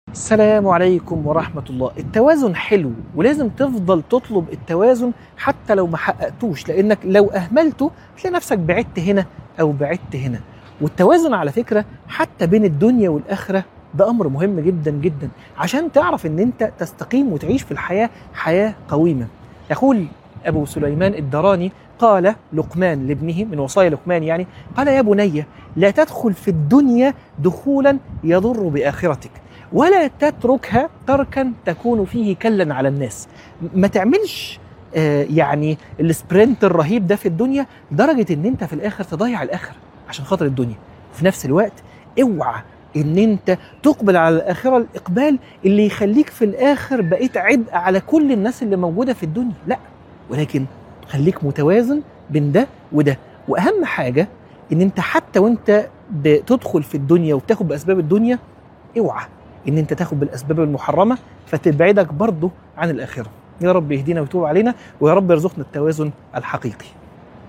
عنوان المادة خليك متوازن - من الحرم